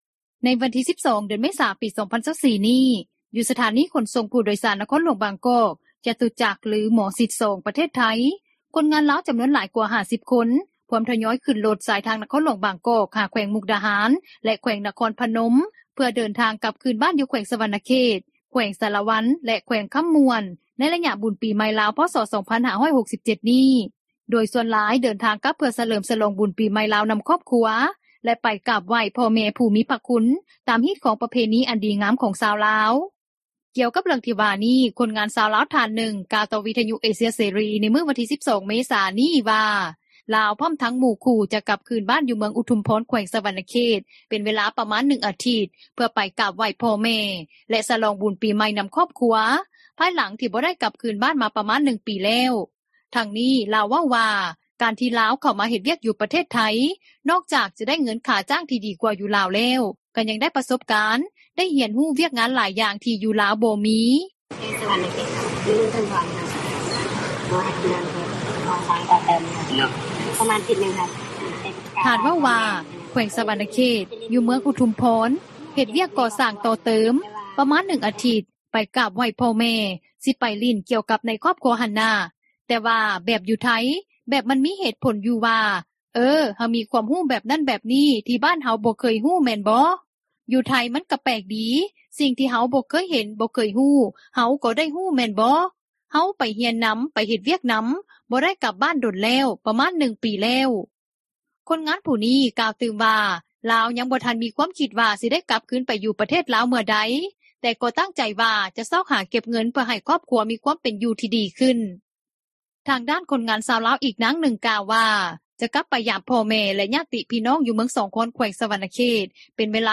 ກ່ຽວກັບເລື່ອງທີ່ວ່ານີ້, ຄົນງານຊາວລາວ ທ່ານໜຶ່ງ ກ່າວຕໍ່ວິທຍຸເອເຊັຽເສຣີ ໃນມື້ວັນທີ 12 ເມສາ ນີ້ວ່າ ລາວ ພ້ອມທັງໝູ່ຄູ່ ຈະກັບຄືນບ້ານ ຢູ່ເມືອງອຸທຸມພອນ ແຂວງສະຫວັນນະເຂດ ເປັນເວລາປະມານ 1 ອາທິດ ເພື່ອໄປກາບໄຫວ້ພໍ່ແມ່ ແລະສະຫຼອງບຸນປີໃໝ່ນຳຄອບຄົວ ພາຍຫຼັງ ທີ່ບໍ່ໄດ້ກັບຄືນບ້ານ ມາປະມານ 1 ປີແລ້ວ. ທັງນີ້, ລາວເວົ້າວ່າ ການທີ່ລາວເຂົ້າມາເຮັດວຽກ ຢູ່ປະເທດໄທ ນອກຈາກຈະໄດ້ເງິນຄ່າຈ້າງ ທີ່ດີກວ່າຢູ່ລາວແລ້ວ ກະຍັງໄດ້ປະສົບການ ໄດ້ຮຽນຮູ້ວຽກງານຫຼາຍຢ່າງ ທີ່ຢູ່ລາວບໍ່ມີ.